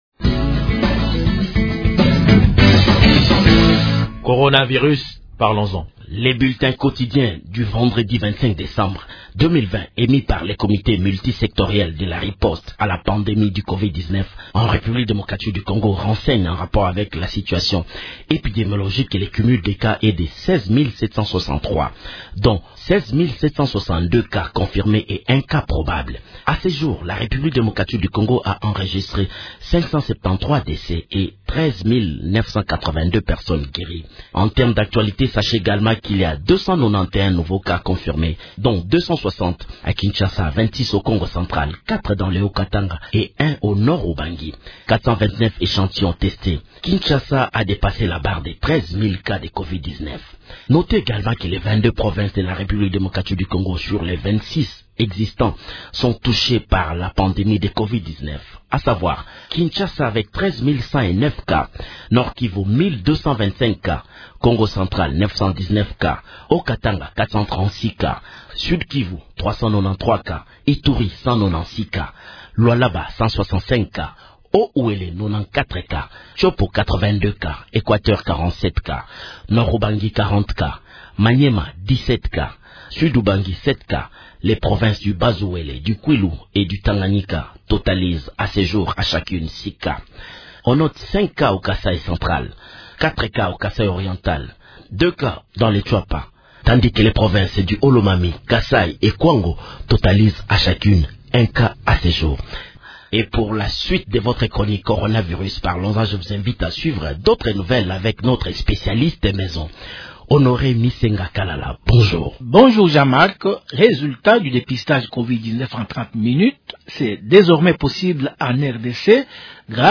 Revenons sur le message de Mgr Gabriel Unda Yemba de l’Eglise Méthodiste-Unie au Congo-Est, au Maniema, qui salue l’instauration du couvre-feu en RDC.